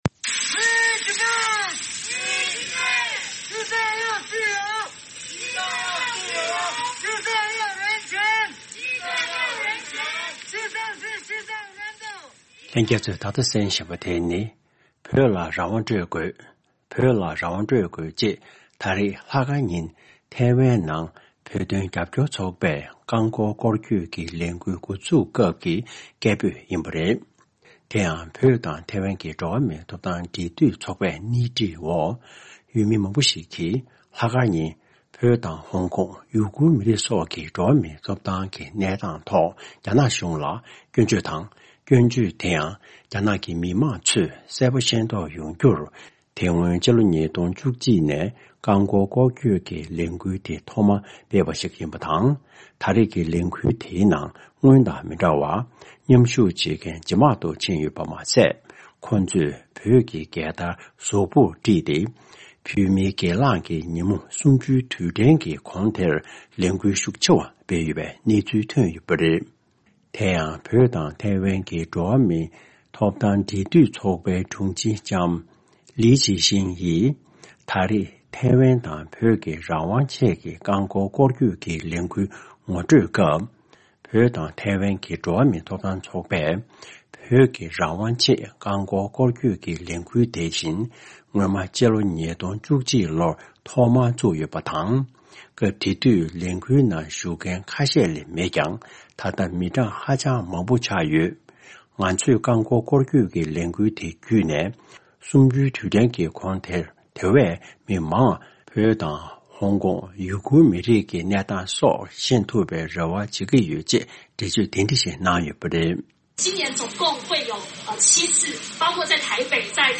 འབྲེལ་ཡོད་མི་སྣར་བཅར་འདྲི་ཞུས་པའི་གནས་ཚུལ་དེ་གསན་རོགས་གནང་།།